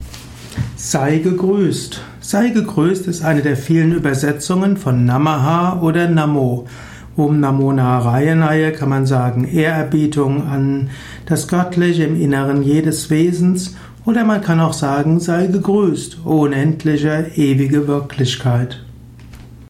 Eine kurzer Abhandlung zum Themenkreis Sei gegrüsst. Simple und komplexe Informationen zum Thema gegrüsst in diesem kleinen kurzen Vortrag.